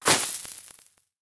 snd_item_gems.ogg